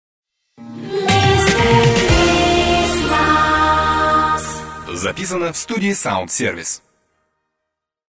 Такие пропевки называются «фирменный джингл».
фирменный джингл